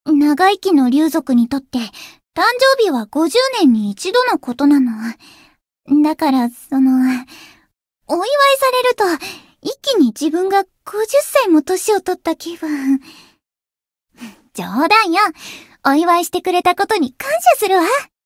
灵魂潮汐-敖绫-人偶生日（相伴语音）.ogg